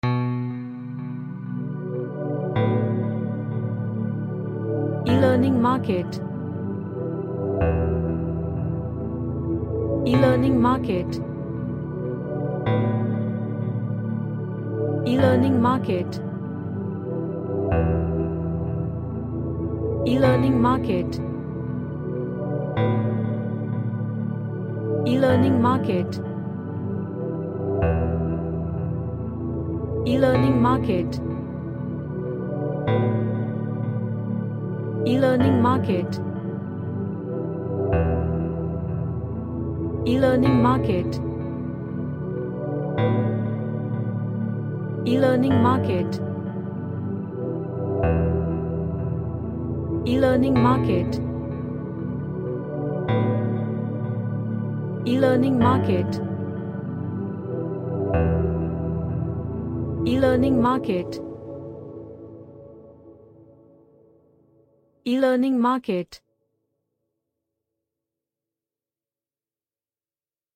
An Ambient track featuring dark pads and guitar.
Dark / Somber